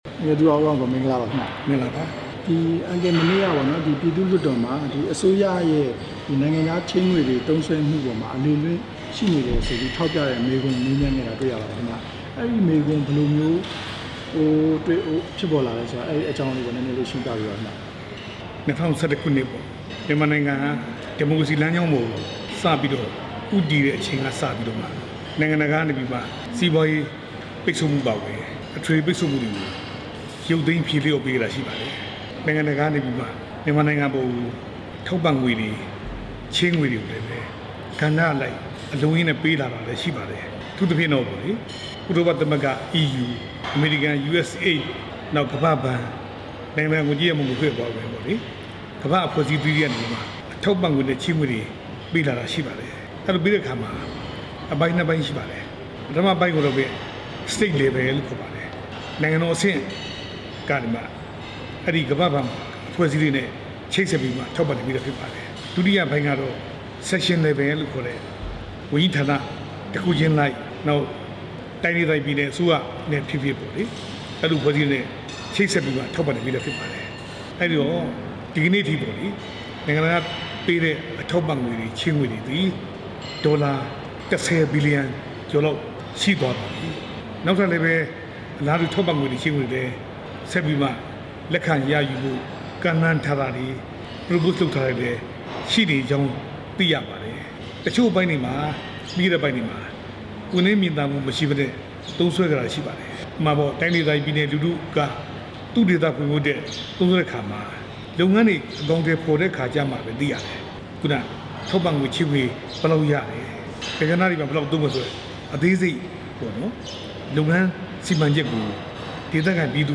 အစိုးရဝန်ကြီးဌာနတွေရဲ့ ငွေကြေးသုံးစွဲမှုနဲ့ ပတ်သက်ပြီး သူရဦးအောင်ကိုက လွှတ်တော်မှာ မနေ့က ဆွေးနွေးခဲ့တာနဲ့ပတ်သက်လို့ RFA မေးမြန်းချက်ကို သူက အခုလို ဖြေကြားခဲ့တာပါ။